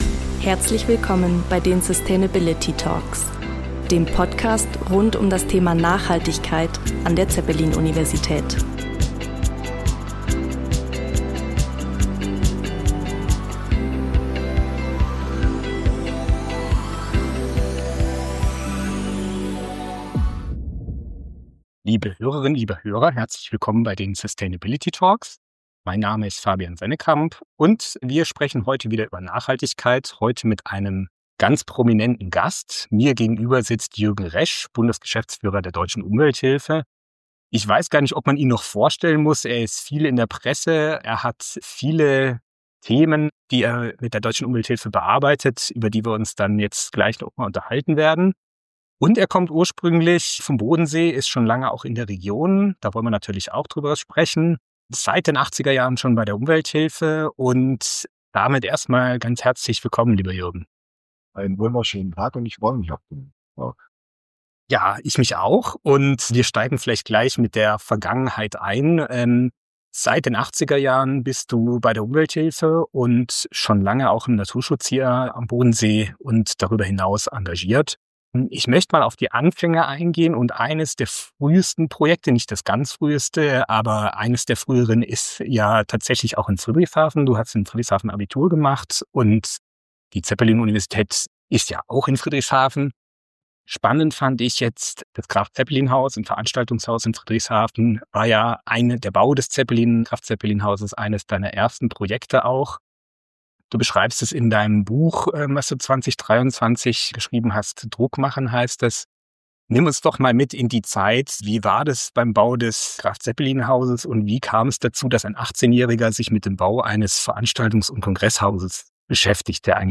Die schwankende Aufnahmequalität bitten wir zu entschuldigen.